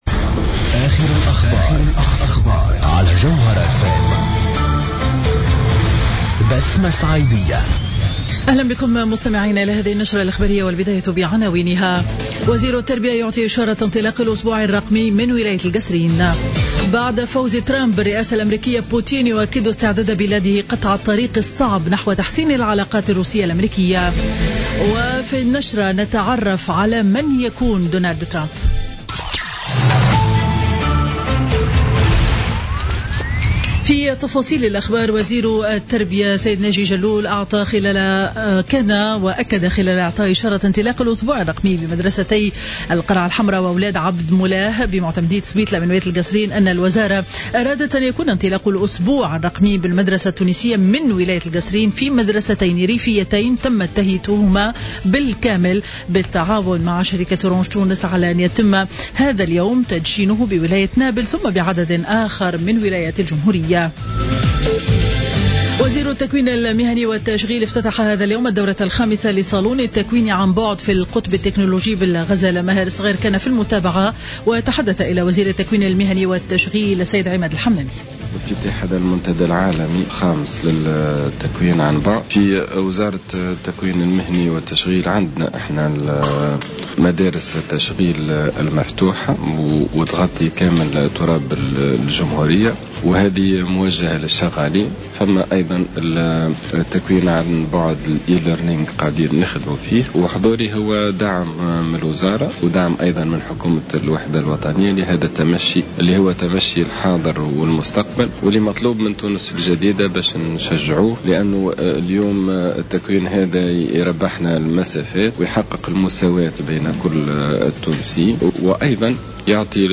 Journal Info 12h00 du mercredi 9 novembre 2016